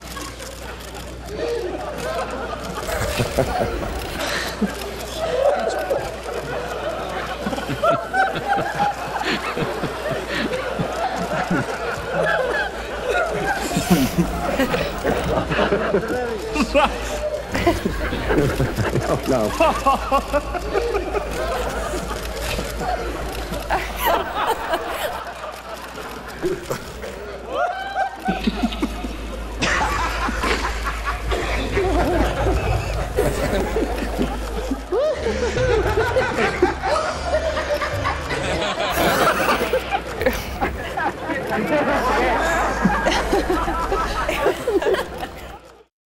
Construite comme une partition musicale, R.I.R.E. est une pièce d’harmonie ou les rires remplacent les instruments, respectent les silences et les crescendos d’une œuvre symphonique.
On y entend : Elvis, Gandhi, Eltsine & Clinton, Poutine, Joker, Dali, Freud, Bacon,  Chaplin, Jung, , Queen Elisabeth, Oppenheimer, Mandela, Trump et beaucoup d’autres réunis dans un rire qui semble ne jamais pouvoir s’arrêter…
• Lady D, Queen Elisabeth, Prince Charles riant aux éclats, ensemble, et pourtant si désunis…
• Jack Nicholson, et tous les différents personnages qu’il a interprété réunis dans un même fou rire.